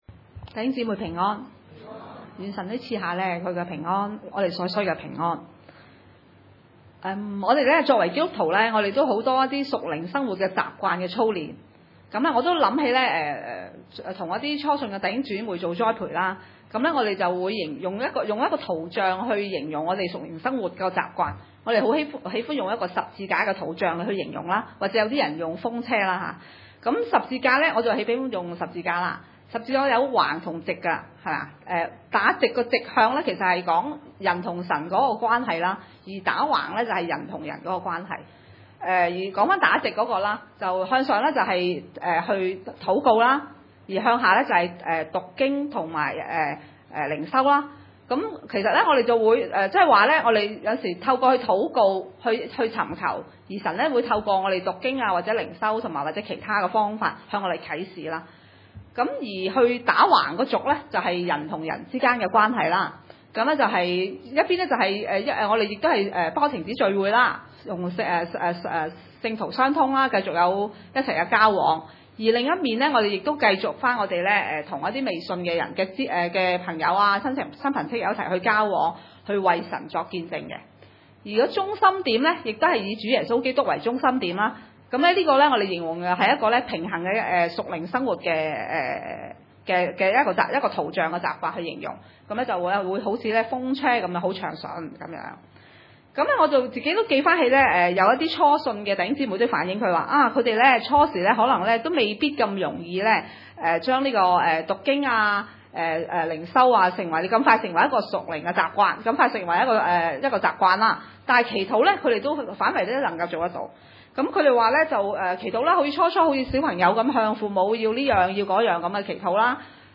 哈巴谷書3章 崇拜類別: 主日午堂崇拜 1先知哈巴谷的禱告，調用流離歌。